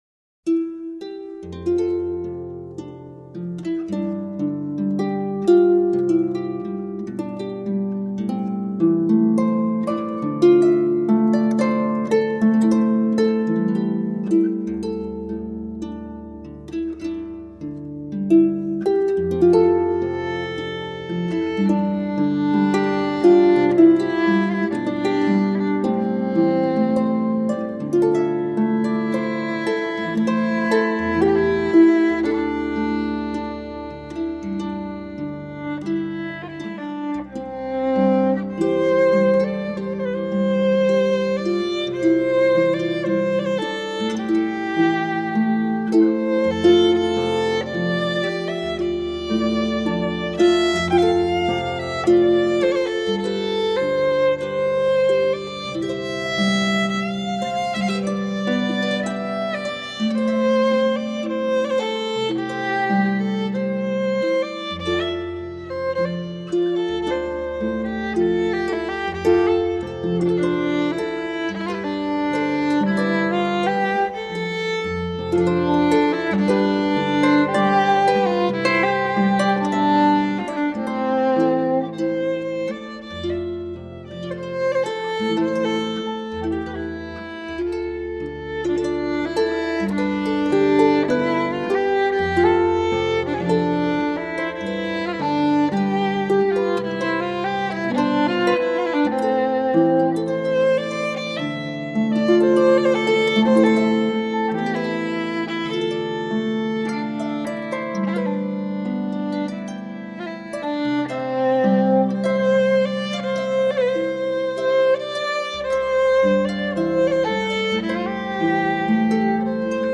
Early song and dance to celebrate midwinter.